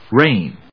/réɪn(米国英語), reɪn(英国英語)/